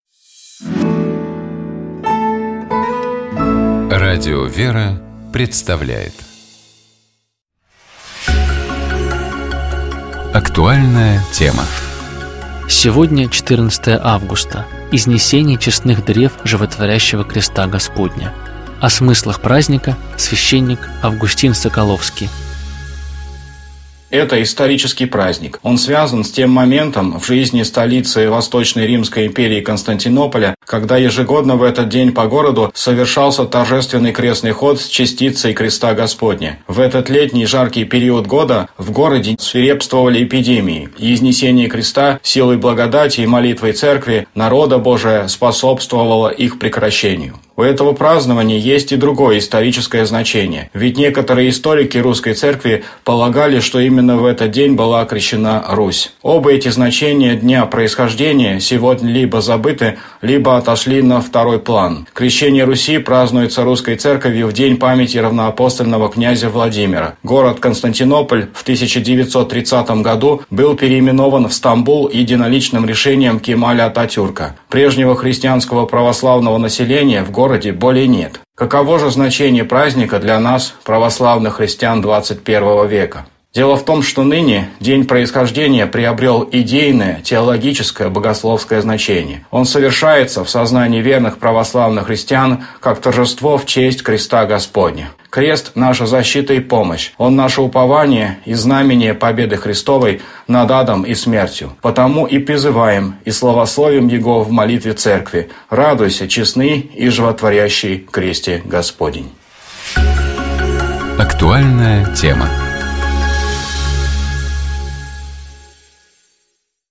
О смыслах праздника, - священник